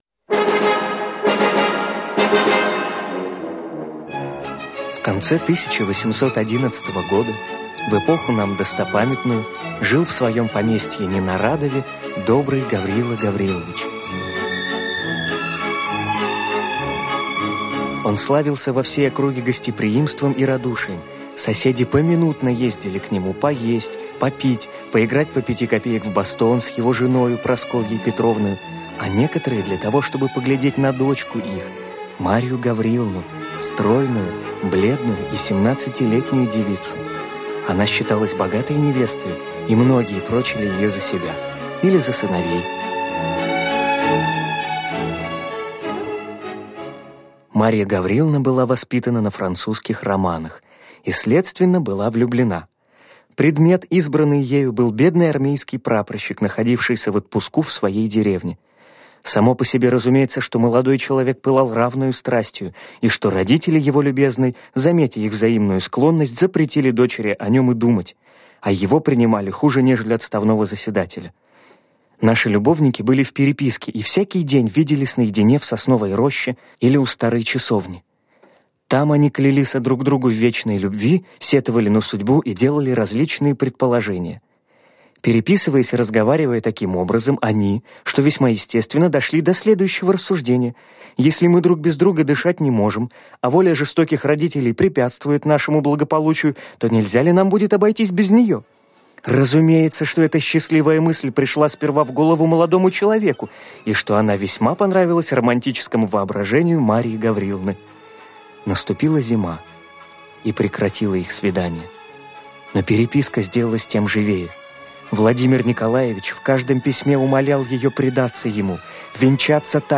Метель - аудио повесть Пушкина - слушать онлайн